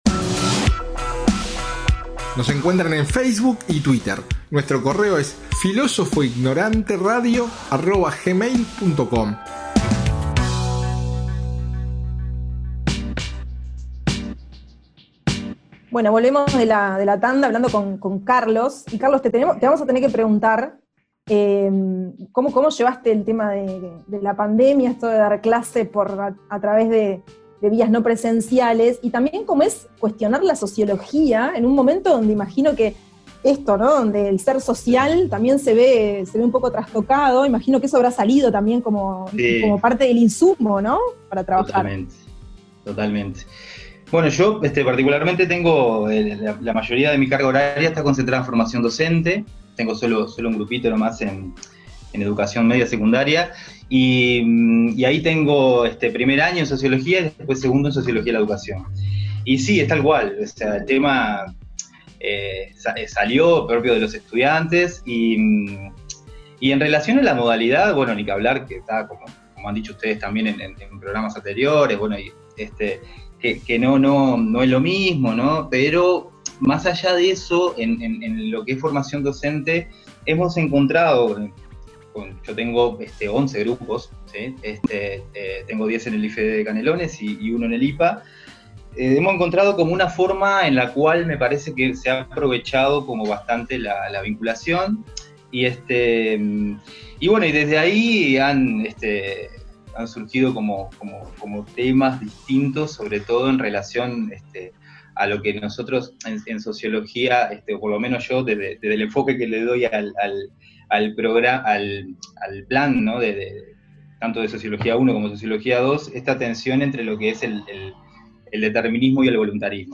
En nuestro nuevo formato de charla